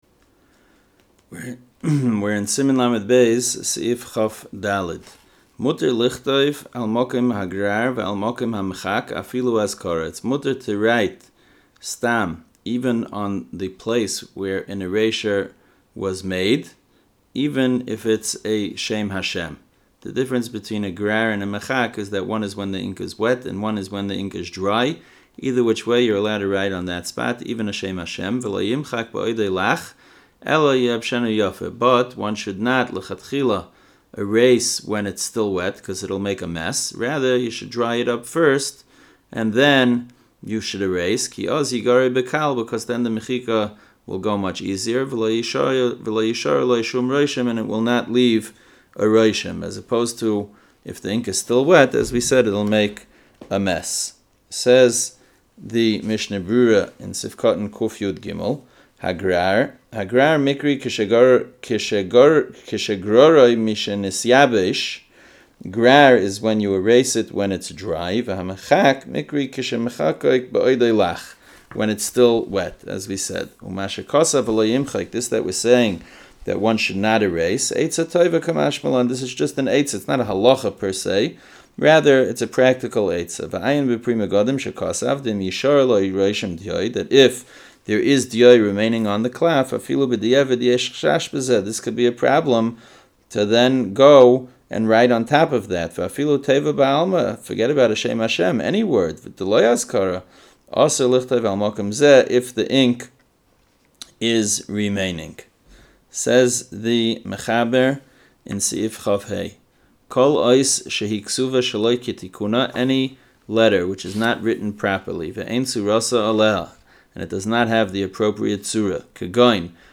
Audio Shiurim - The STa"M Project | Kosher-Certified Mezuzos, Tefillin & STa”M